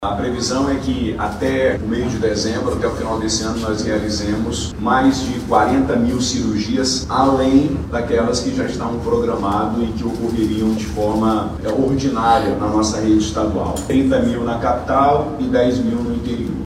A expectativa é que a meta de 40 mil cirurgias seja alcançada ainda neste ano, contribuindo para a redução das longas filas de espera que por tanto tempo afetaram a população, explica o Governador Wilson Lima.
SONORA-1-WILSON-LIMA.mp3